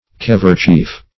Keverchief \Kev"er*chief\, n.